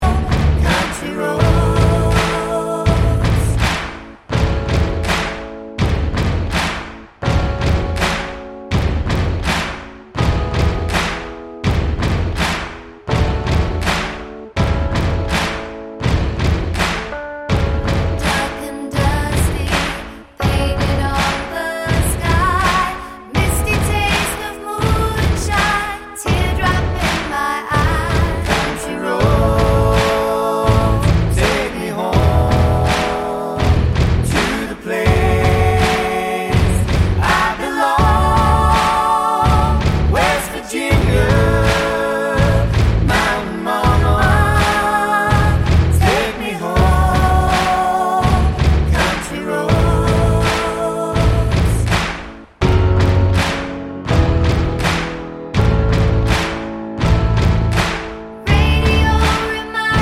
no Backing Vocals Mashups 3:10 Buy £1.50